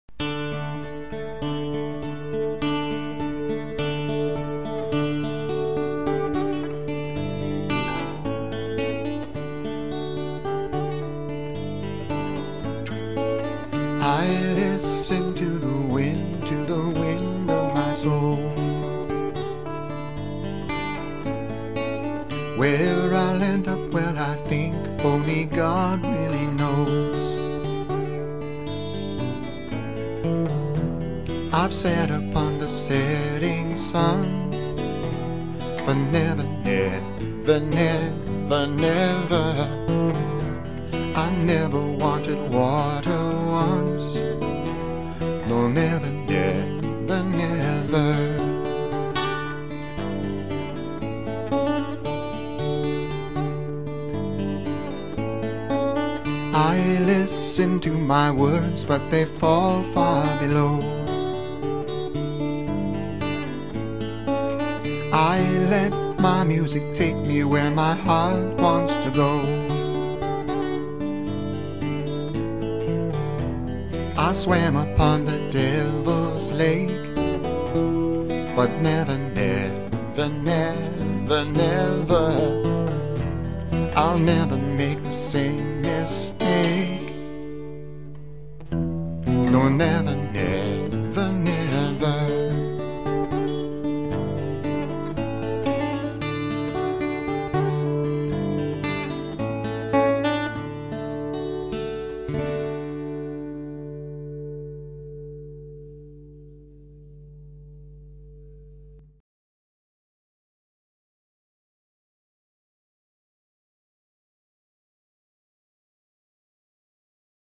Covers
Acoustic guitar.